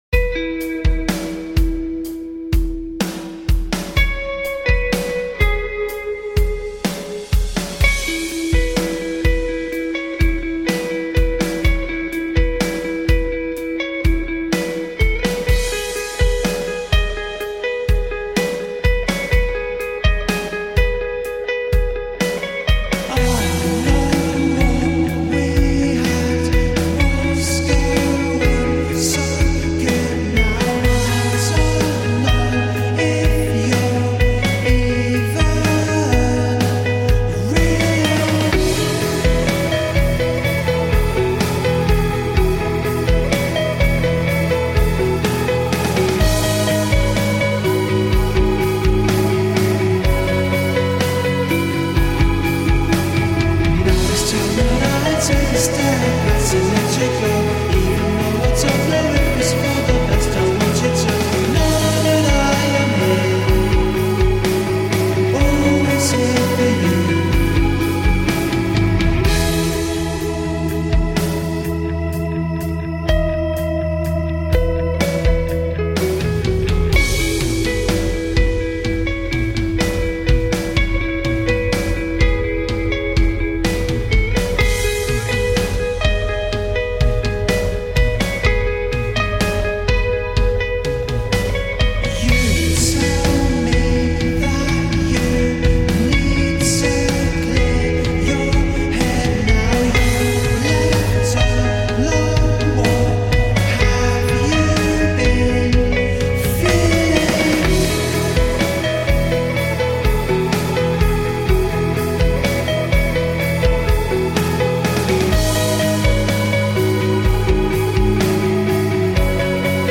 dream pop quartet